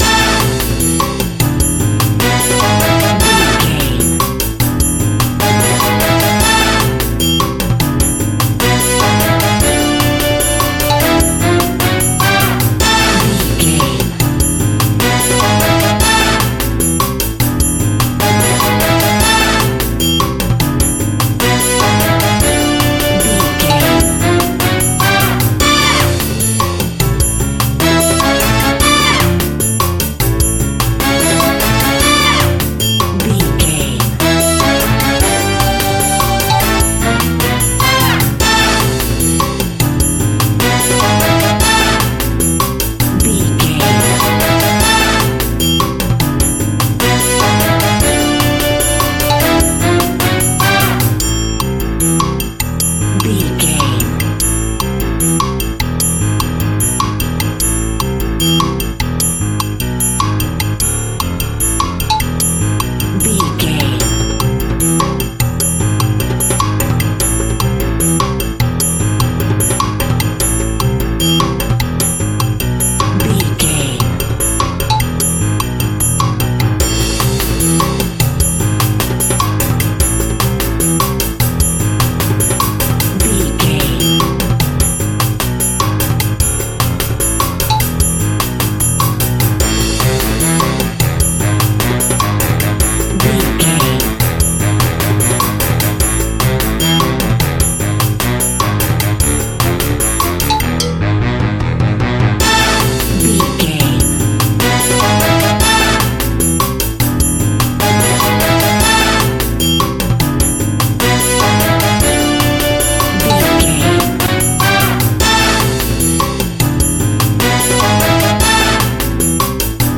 Aeolian/Minor
World Music
piano
brass
ethnic percussion